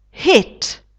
hit [hit]